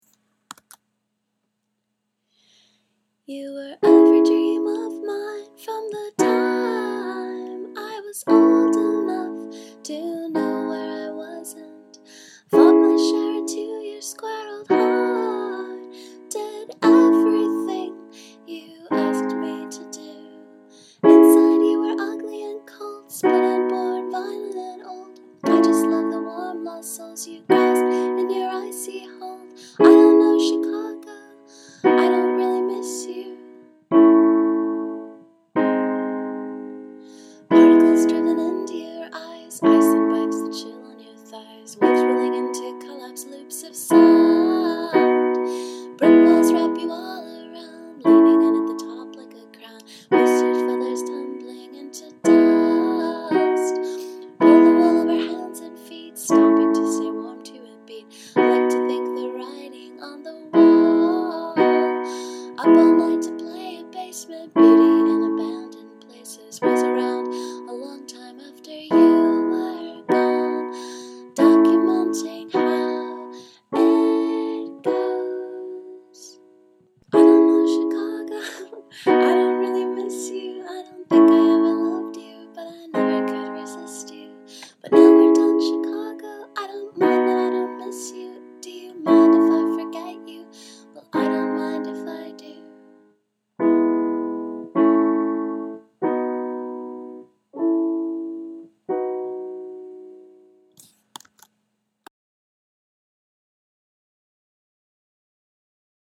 C#m B A G#
E A B E B
A, B, A
i spent a bunch of time tonight "recording" bits of songs i've been working on, before realizing i don't know how to make a mac record from line in so i was just recording the sound of the keys being pressed down. this was slightly better so here it is.